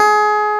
CLAV C4+.wav